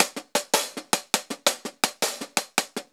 Closed Hats
HIHAT_TAKE_U_FOR_A_RIDE.wav